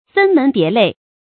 注音：ㄈㄣ ㄇㄣˊ ㄅㄧㄝ ˊ ㄌㄟˋ
分門別類的讀法